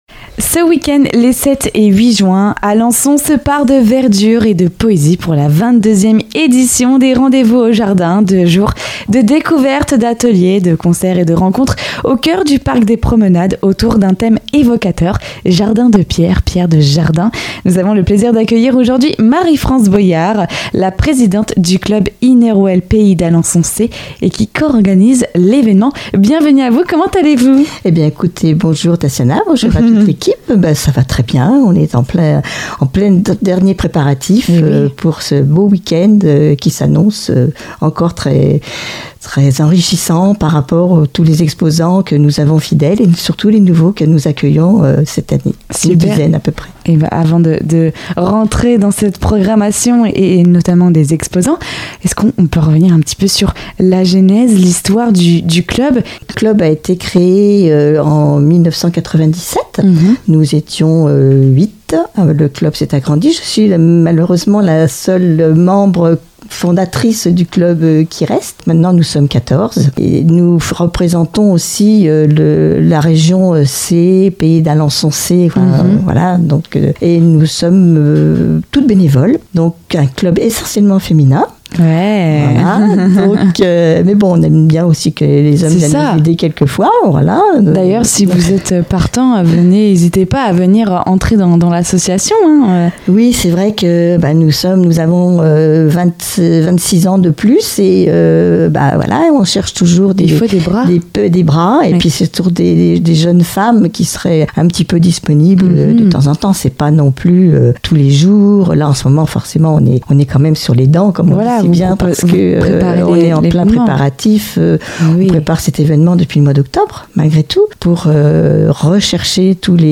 Pour en savoir plus sur cet rencontre n'hésitez pas à écouter jusqu'au bout l'interview.